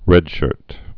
(rĕdshûrt)